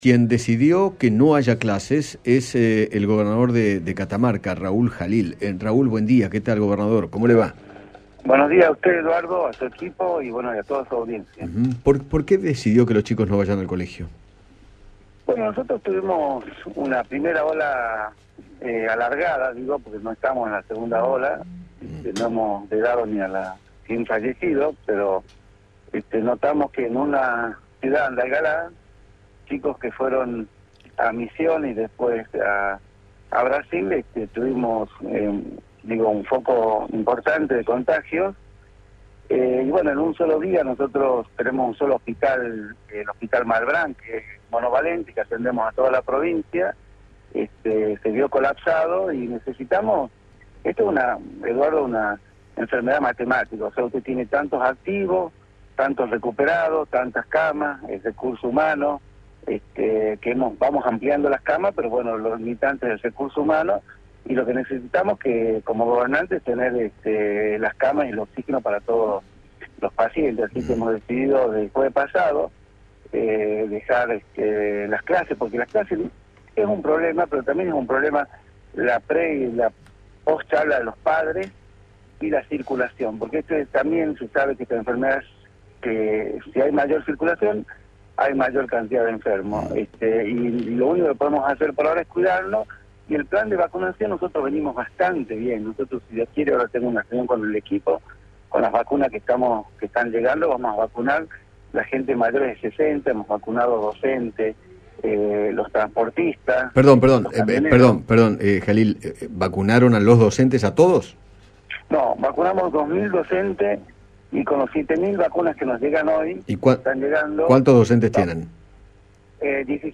Raúl Jalil, gobernador de aquella provincia, dialogó con Eduardo Feinmann acerca de las razones que lo llevaron a decidir el cierre de escuelas pese a la baja tasa de contagios en los centros educativos.